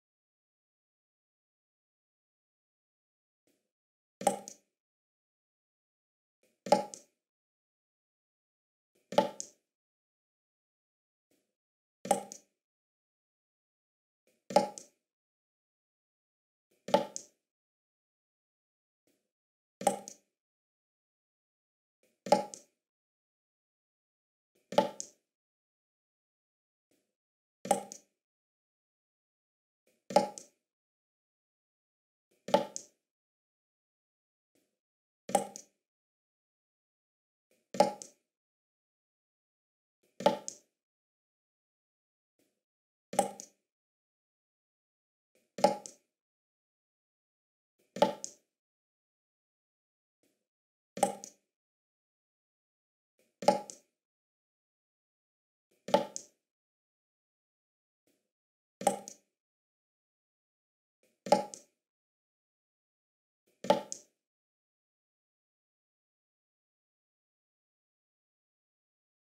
دانلود آهنگ چکه چکه قطره آب 2 از افکت صوتی طبیعت و محیط
دانلود صدای چکه چکه قطره آب 2 از ساعد نیوز با لینک مستقیم و کیفیت بالا
جلوه های صوتی